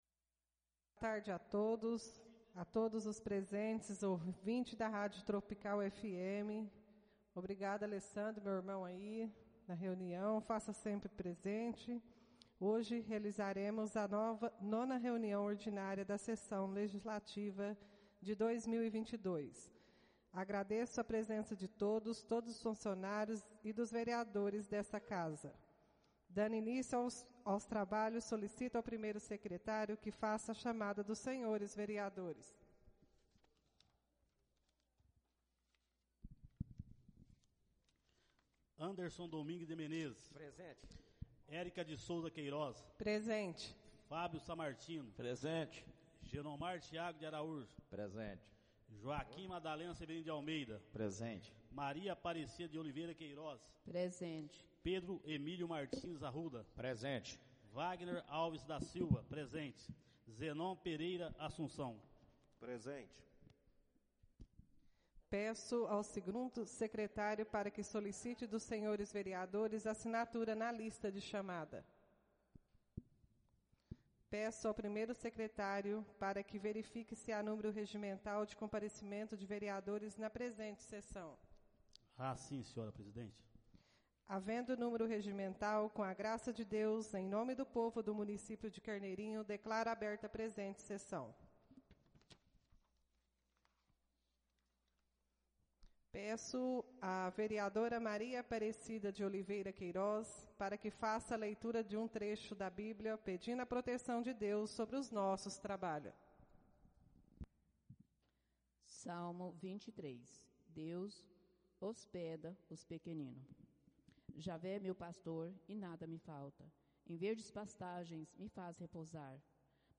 Áudio da 9.ª reunião ordinária de 2022, realizada no dia 06 de Junho de 2022, na sala de sessões da Câmara Municipal de Carneirinho, Estado de Minas Gerais.